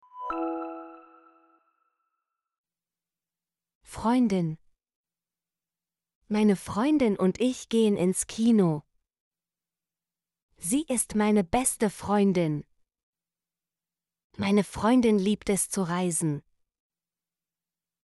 freundin - Example Sentences & Pronunciation, German Frequency List